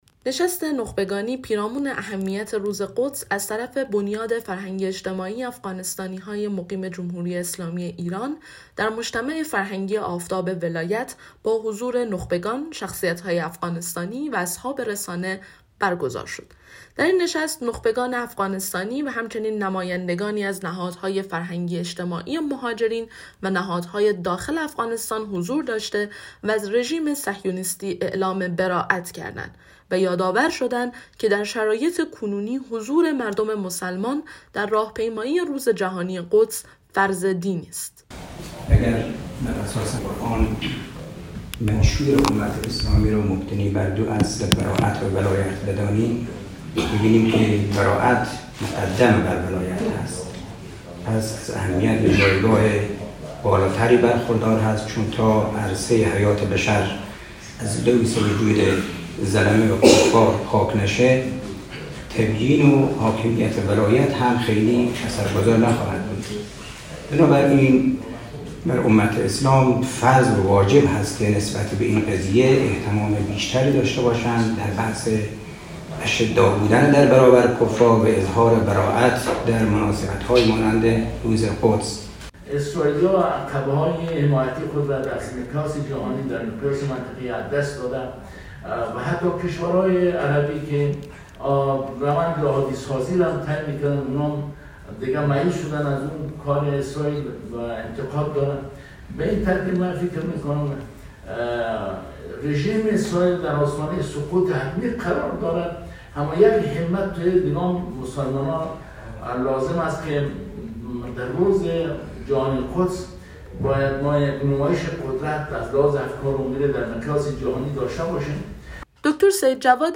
نشست نخبگانی پیرامون اهمیت روز قدس از طرف بنیاد فرهنگی اجتماعی افغانستانی های مقیم جمهوری اسلامی ایران در مجتمع فرهنگی آفتاب ولایت با حضور نخبگان، شخصیت ها...